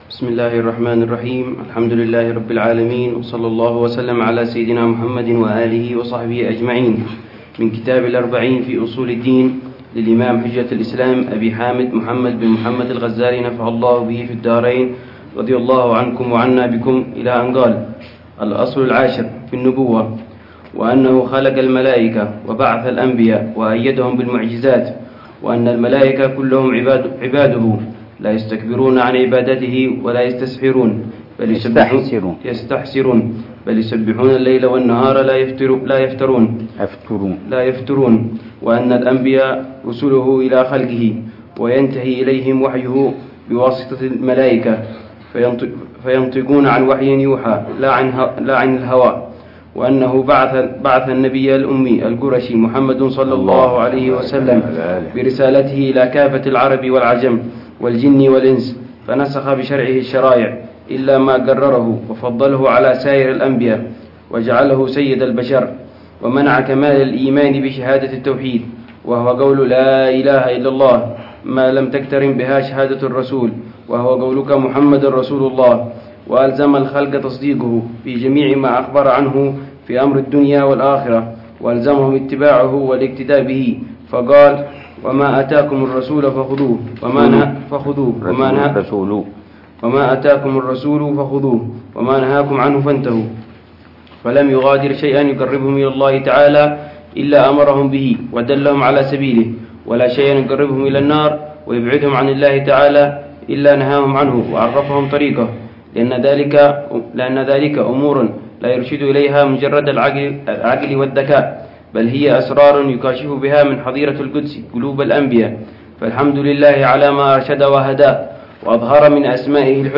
الدرس الرابع للعلامة الحبيب عمر بن محمد بن حفيظ في شرح كتاب: الأربعين في أصول الدين، للإمام الغزالي .